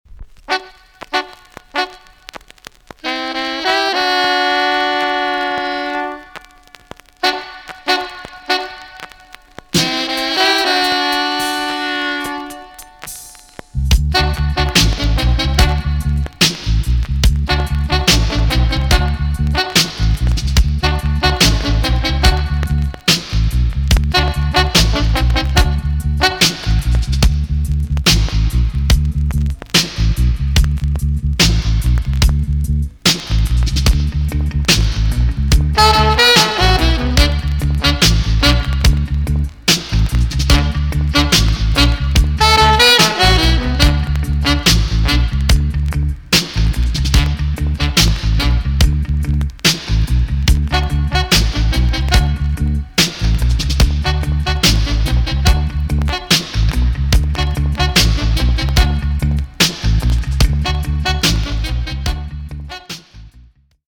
TOP >REGGAE & ROOTS
B.SIDE Version
EX-~VG+ 少し軽いチリノイズがありますが良好です。